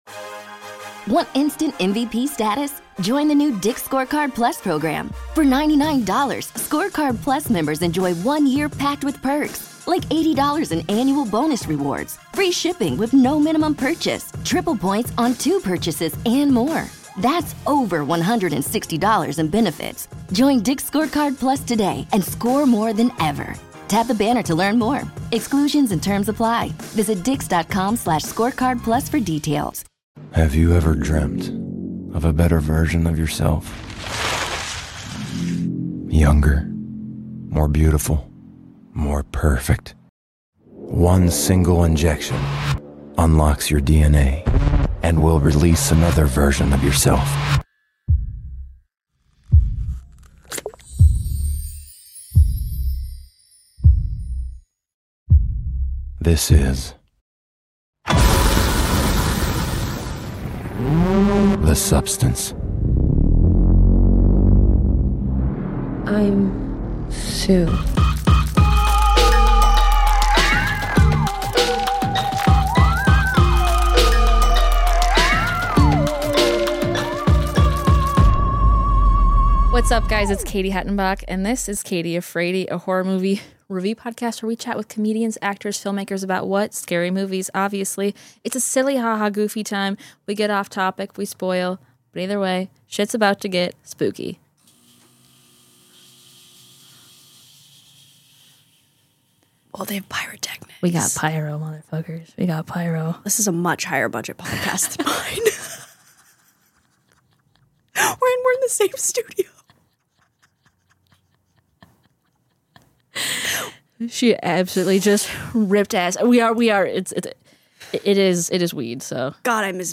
horror movie review podcast
talks with comedians, actors, and filmmakers about horror movies!